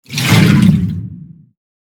AGUA WATER WOOSH
Ambient sound effects
Descargar EFECTO DE SONIDO DE AMBIENTE AGUA WATER WOOSH - Tono móvil
agua_Water_woosh.mp3